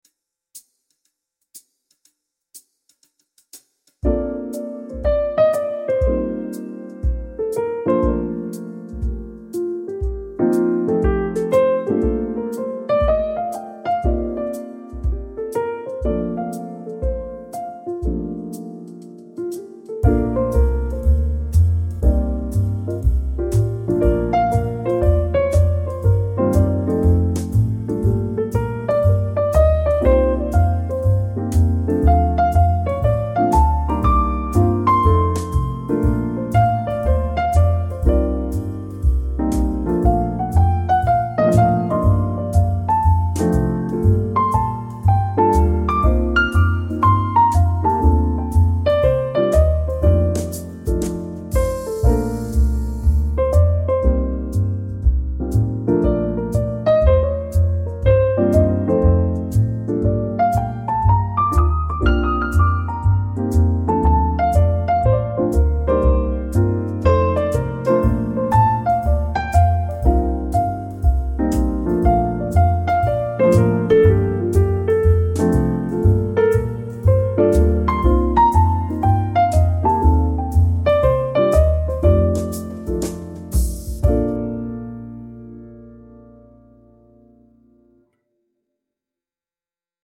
sophisticated jazz-influenced piano with smooth brushed drums